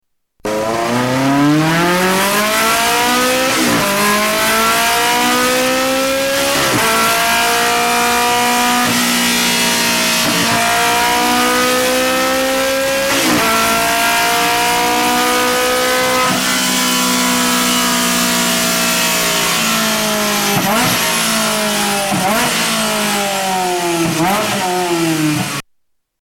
Ferrari 360challenge